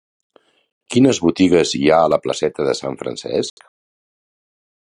Pronunciat com a (IPA) [fɾənˈsɛsk]